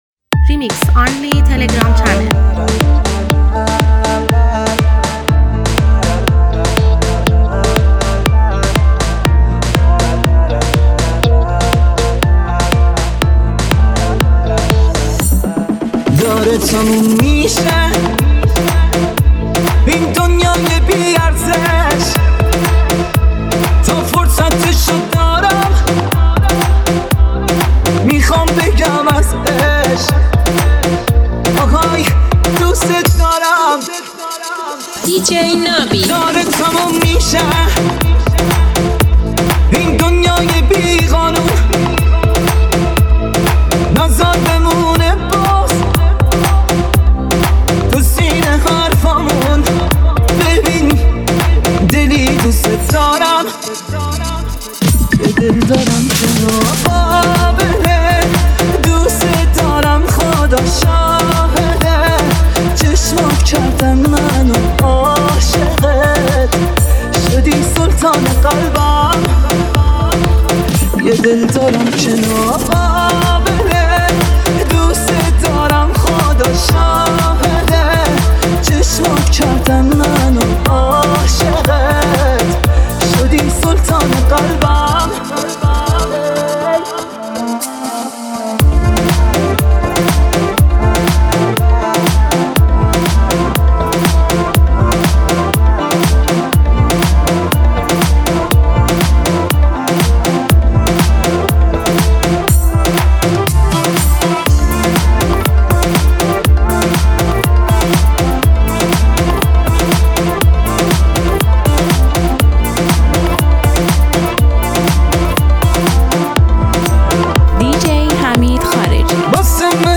ریمیکس عاشقانه و احساسی